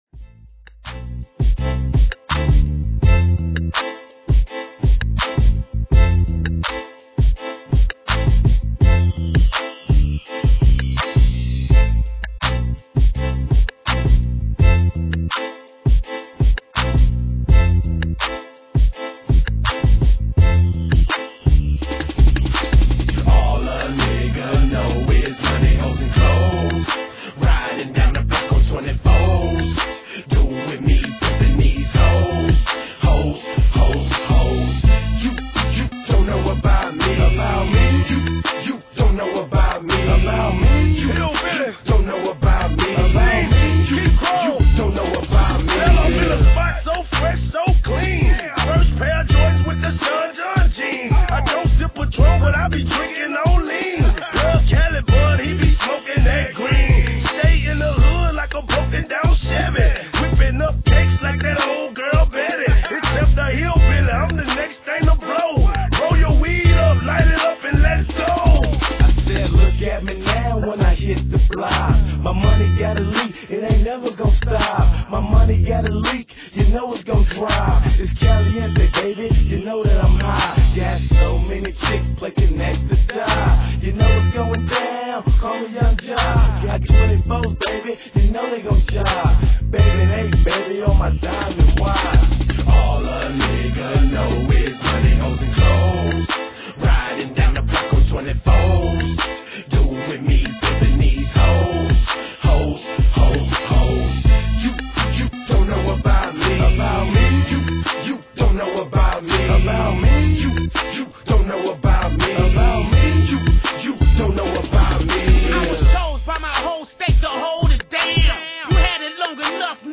Tags: rap, mp3